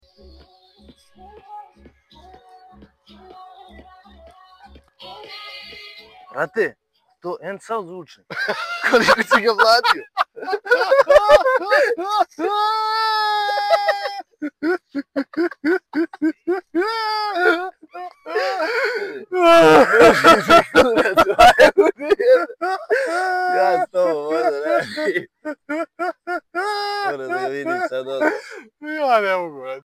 Haha HA Haha Sound Effects Free Download